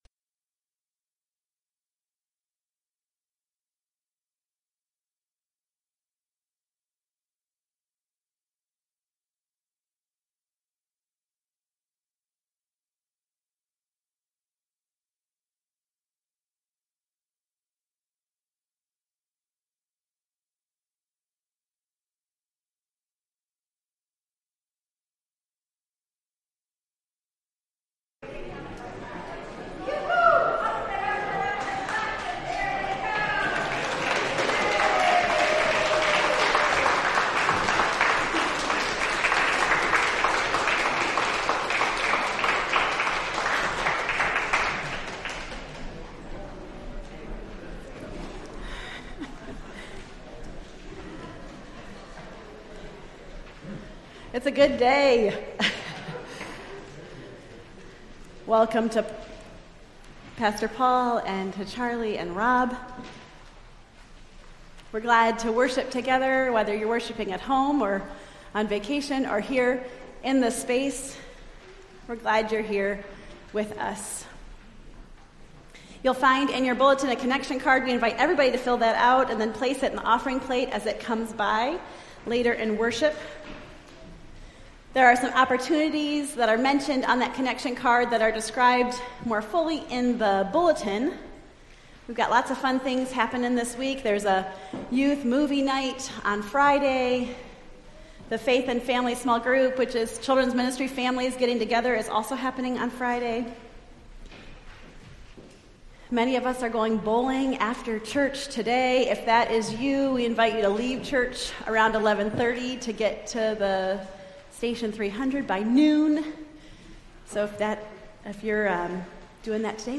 Entire April 16th Service
4-16-23-service.mp3